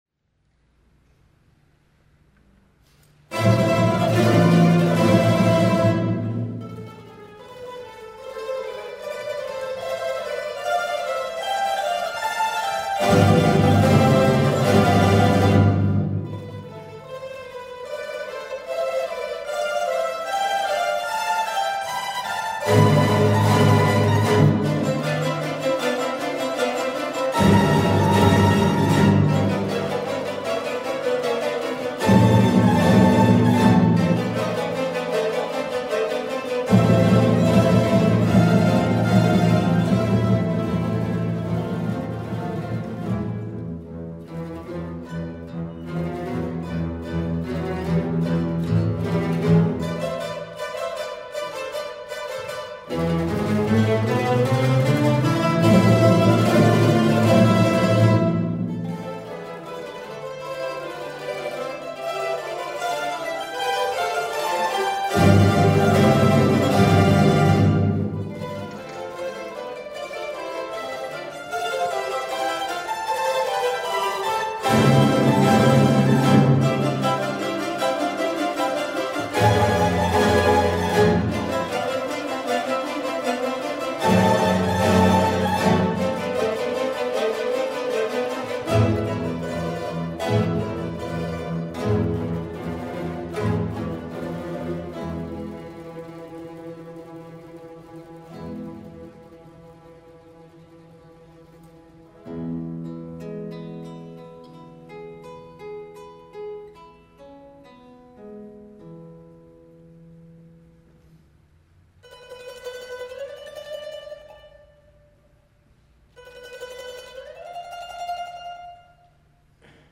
駒ヶ根マンドリーノ　第３1回定期演奏会
会　場　　　　　駒ヶ根市文化会館　大ホール